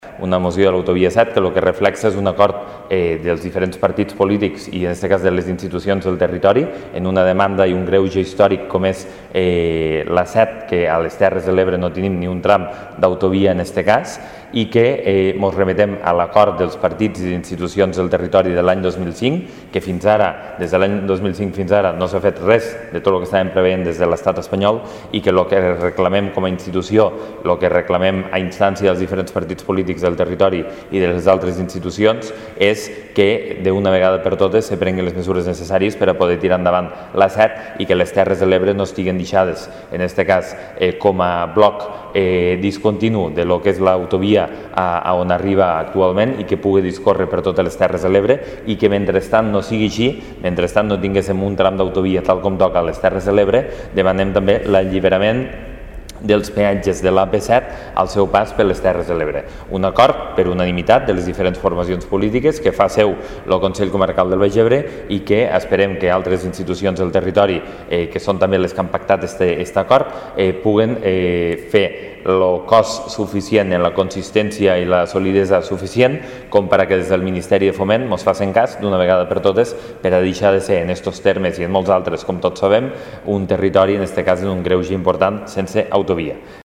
En aquest sentit, el president de l’ens comarcal del Baix Ebre, Lluís Soler, ha destacat que “d’una vegada per totes s’han de prendre les mesures adients per tal d’engegar aquest projecte necessari per a les Terres de l’Ebre i finalitzar, d’aquesta manera, un greuge històric que s’arrossega des de l’any 2005”.
(tall de veu) Lluís Soler detalla l'acord per a la reclamació de l'execució de les obres de l'autovia A7